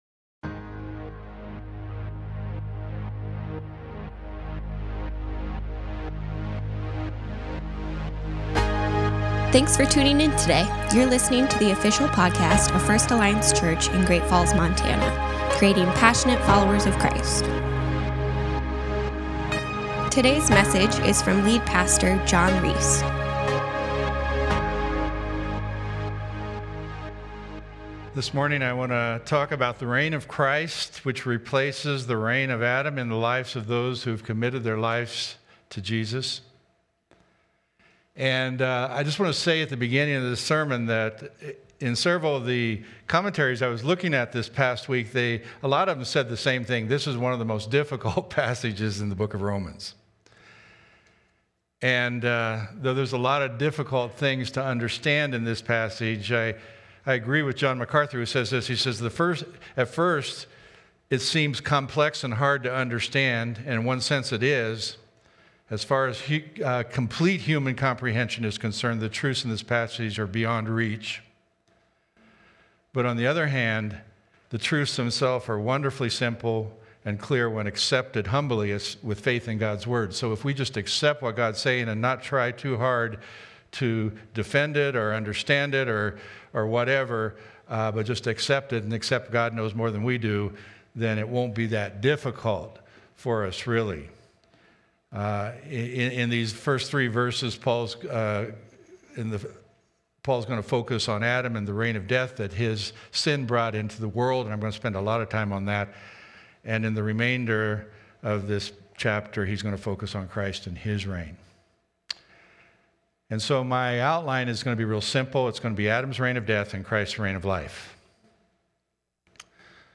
Sermons | First Alliance Church